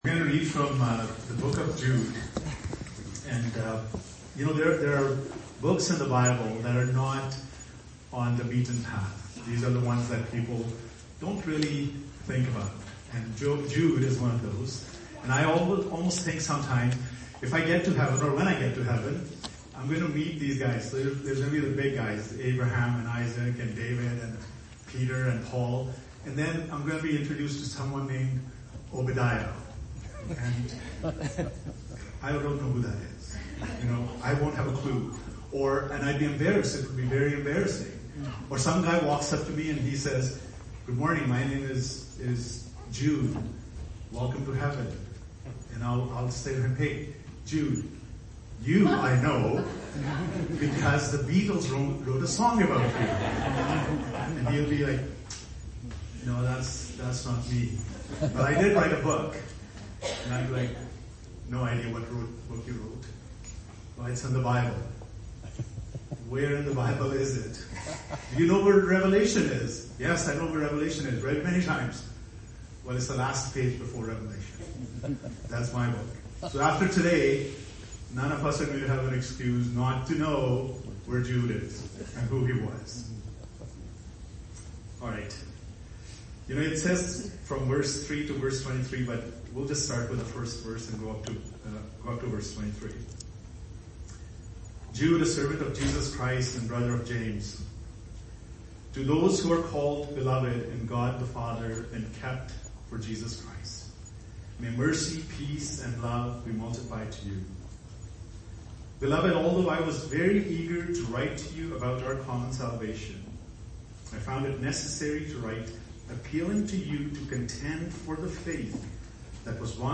Sermons - Whiteshell Baptist Church